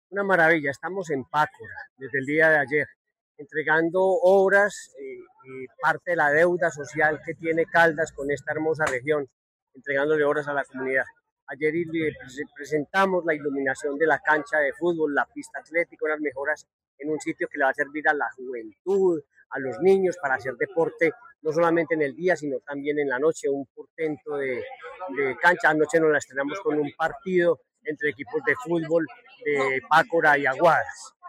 Henry Gutiérrez, gobernador de Caldas